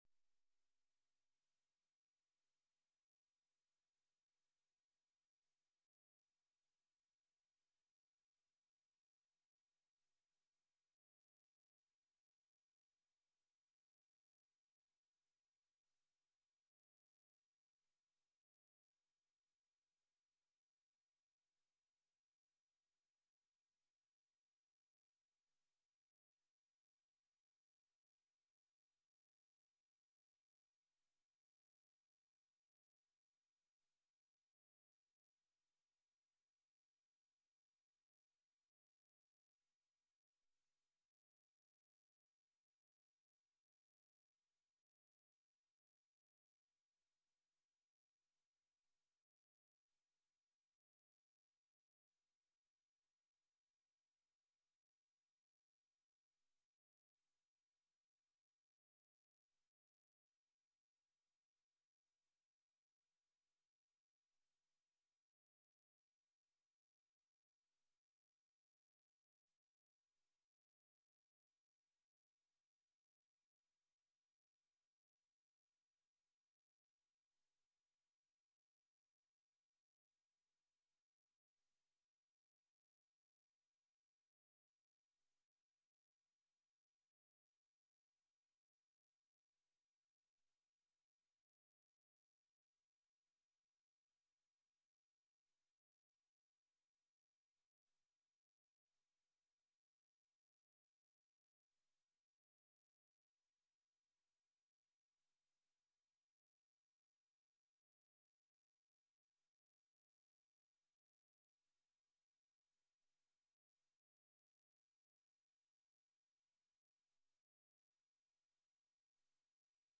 Live from New Ear Inc: New Ear Inc (Audio) Oct 13, 2024 shows Live from New Ear Inc Live from Fridman Gallery Live from New Ear at Fridman Gallery, NYC Play In New Tab (audio/mpeg) Download (audio/mpeg)